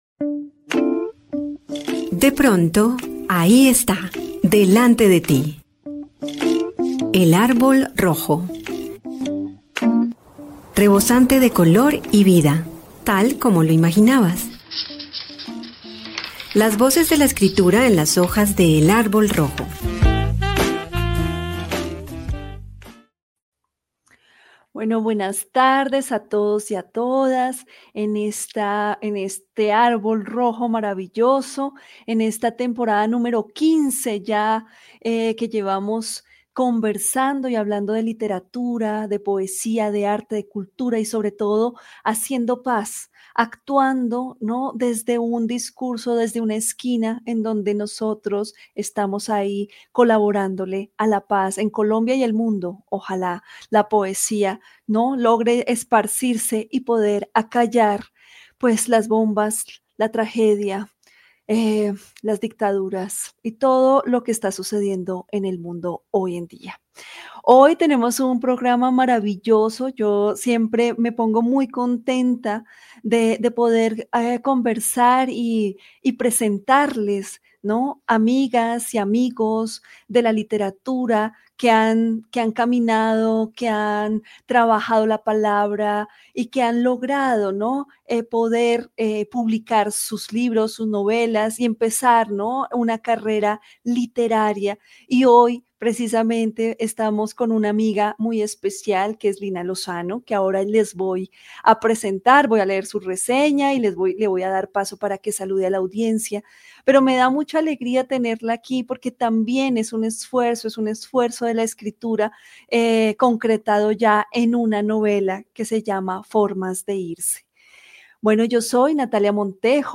Comentario al Evangelio de hoy